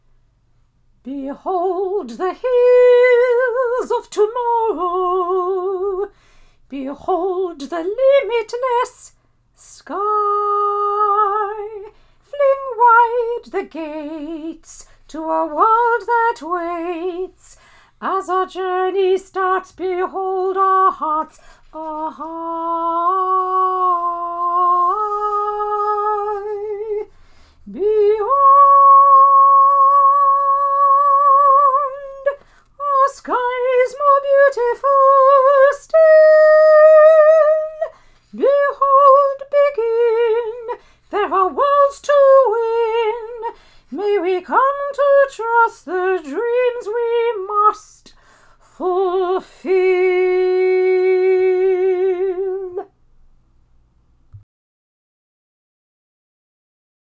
Sop 1